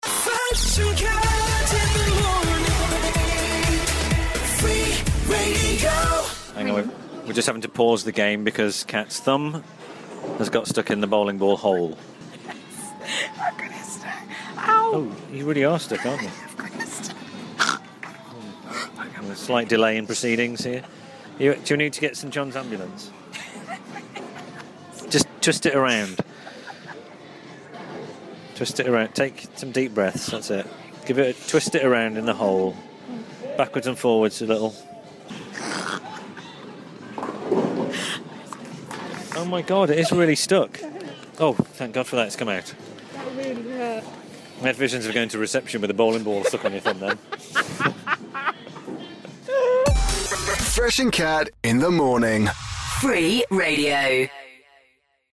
Thanks to Hollywood Bowl, Bentley Bridge for welcoming us.